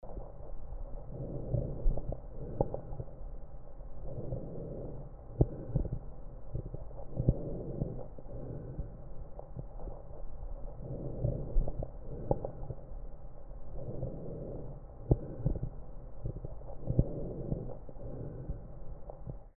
健常例 2